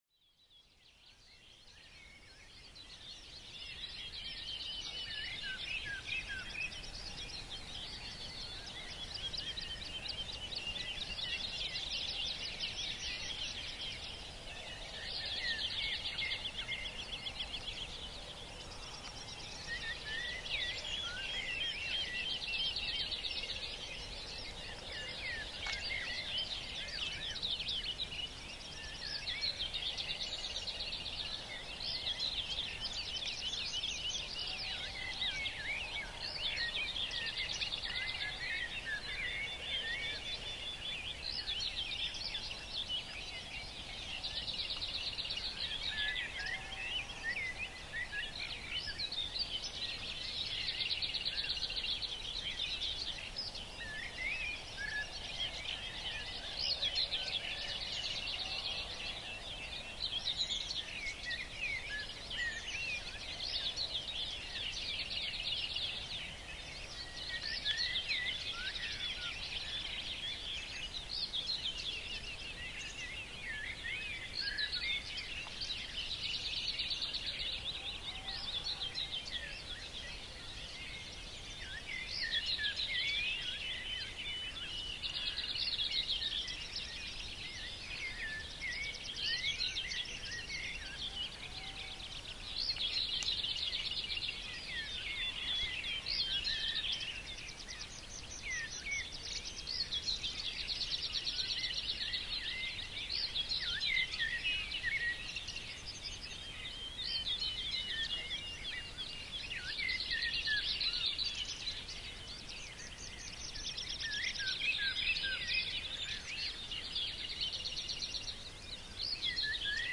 Fjällmossen, ett av våra unika naturreservat, har valts ut som en plats där tystnaden får ta plats.
Med en uppmätt ljudnivå på endast 44 decibel erbjuder Fjällmossen en miljö där naturens egna ljud får dominera.
The Map of Quietude är en digital karta där du kan utforska Skånes tystaste platser och lyssna på timslånga inspelningar från varje plats.